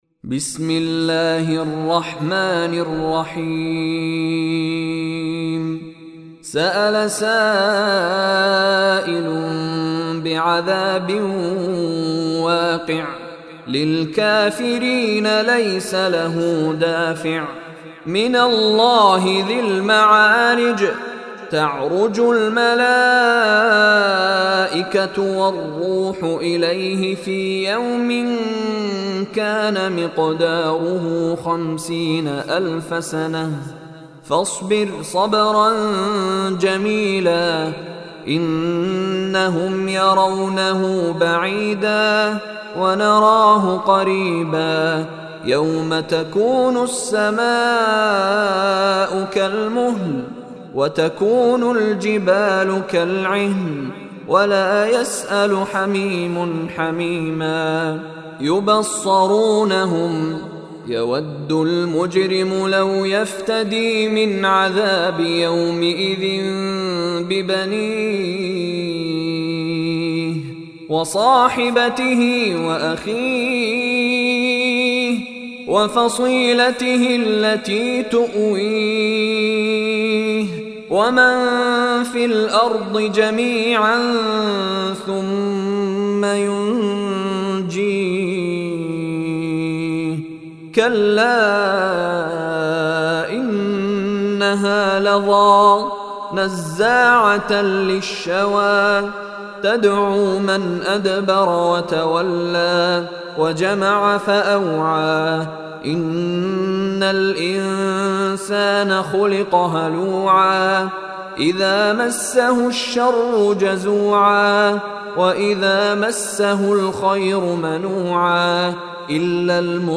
Surah Al Maarij Recitation by Mishary Rashid
Surah Al-Maarij tilawat / recitation audio mp3. Listen online and download mp3 tilawat in the voice of Sheikh Mishary Rashid Alafasy.